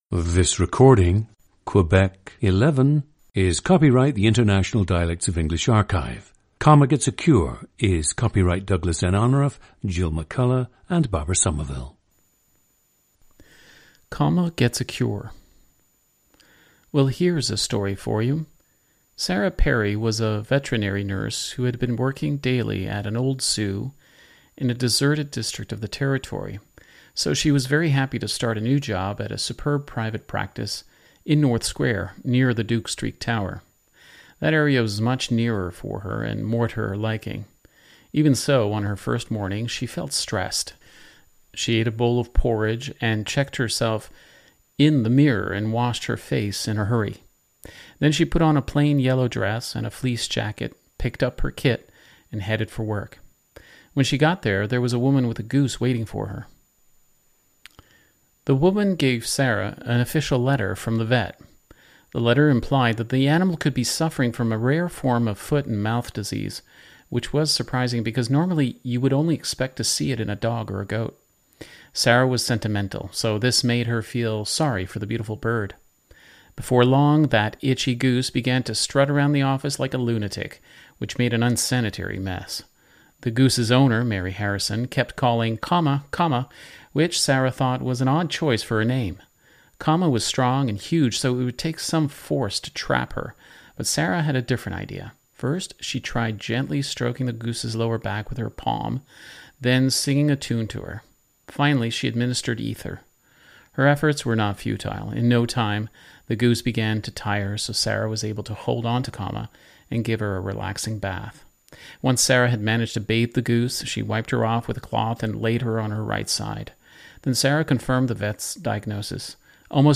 Listen to Quebec 11, a 55-year-old man from Montreal, Quebec, Canada.
GENDER: male
He attended only English-language elementary and high schools.
The text used in our recordings of scripted speech can be found by clicking here.
ORTHOGRAPHIC TRANSCRIPTION OF UNSCRIPTED SPEECH: